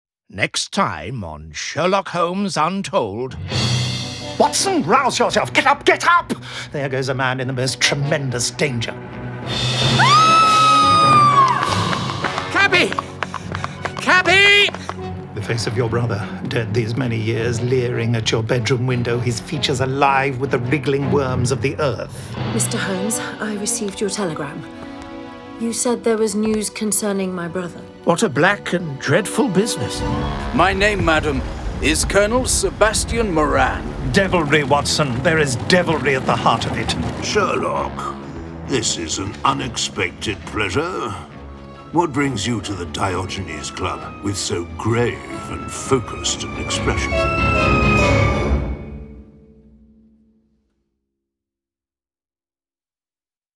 full-cast original audio dramas